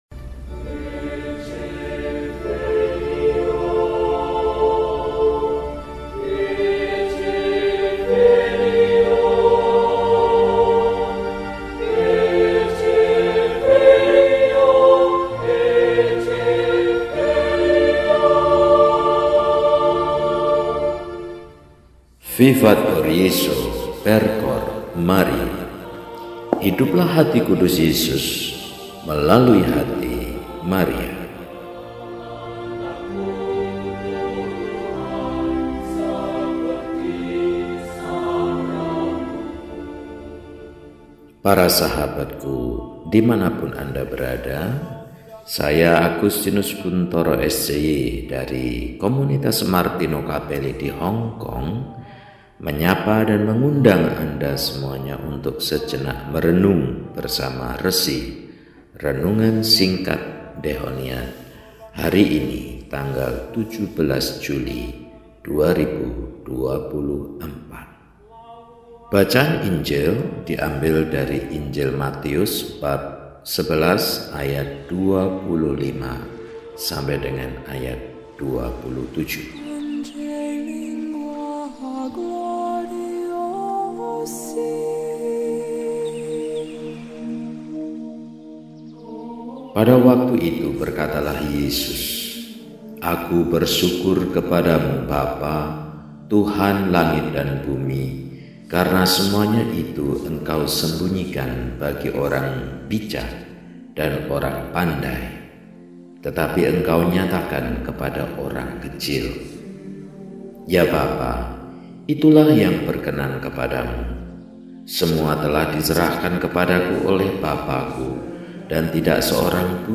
Rabu, 17 Juli 2024 – Hari Biasa Pekan XV – RESI (Renungan Singkat) DEHONIAN